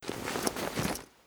looting_6.ogg